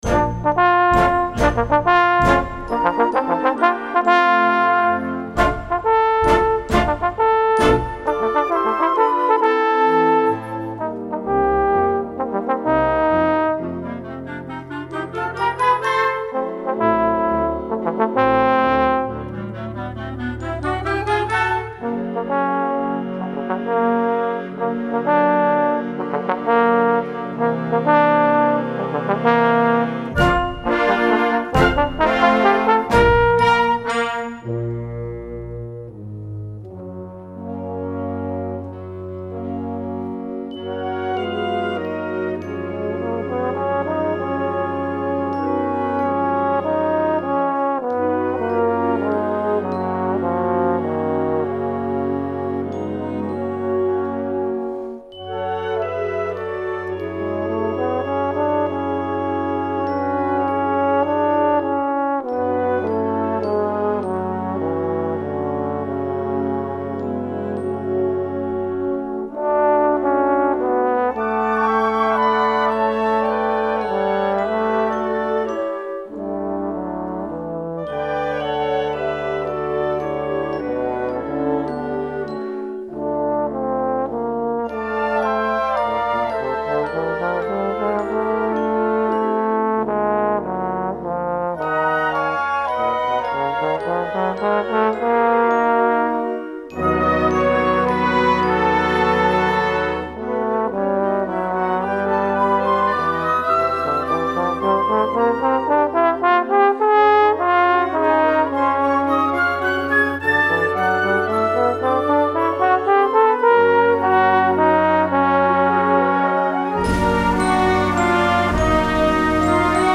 Voicing: Trombone w/ Band